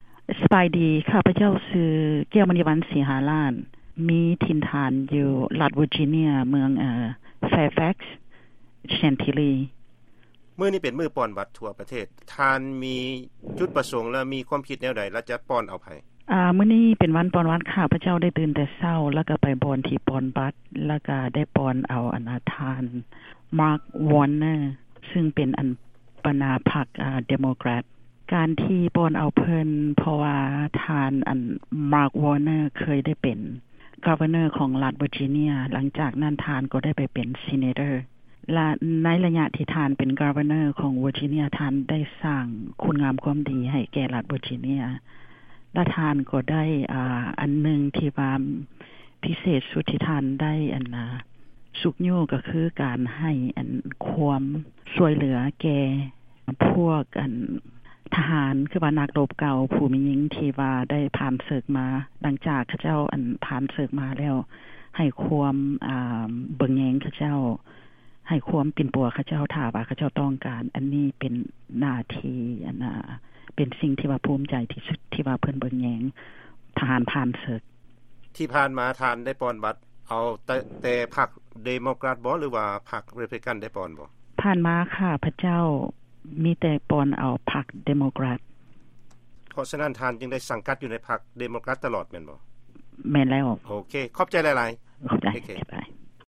ສຸພາບສະຕີ ລາວ-ອາເມຣິກັນ